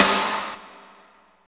55.06 SNR.wav